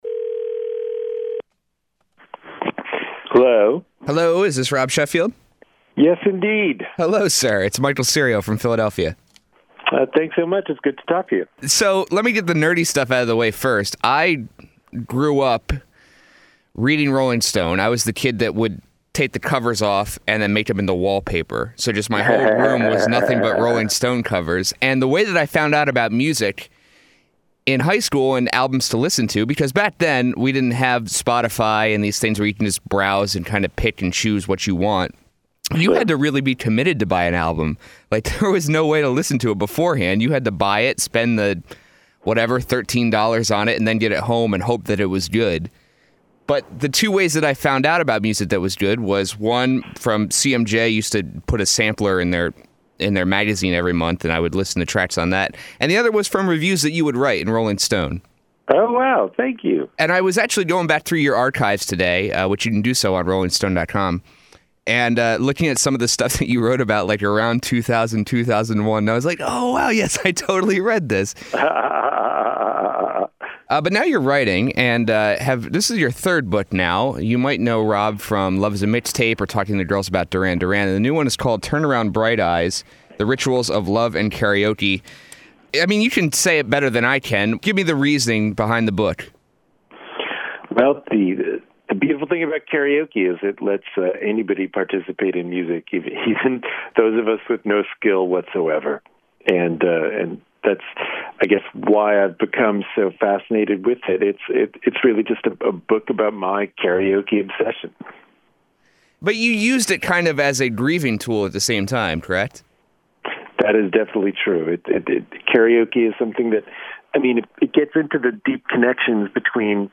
rob-sheffield-interview.mp3